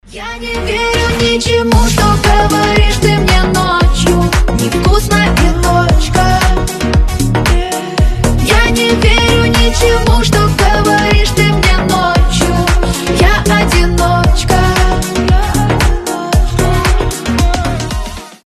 • Качество: 320, Stereo
женский голос
Dance Pop